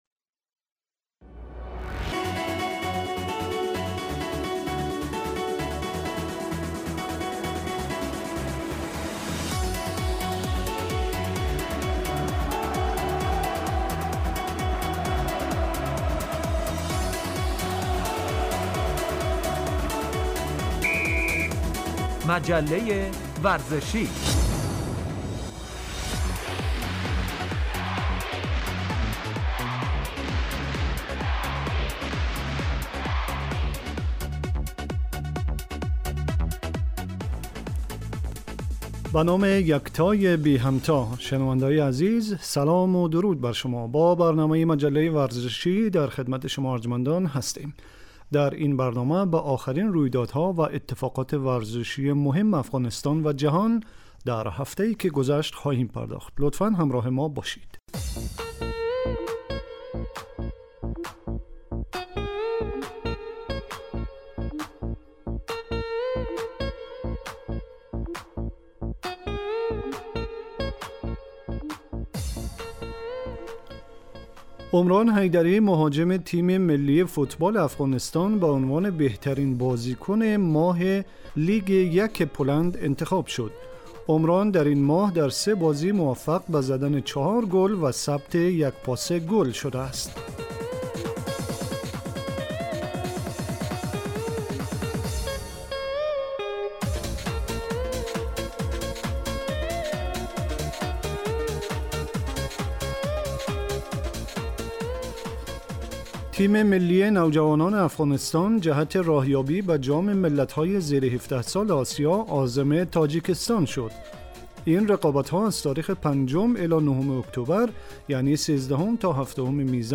آخرين اخبار و رويدادهاي ورزشي افغانستان و جهان در هفته اي که گذشت به همراه گزارش و مصاحبه وبخش ورزش وسلامتي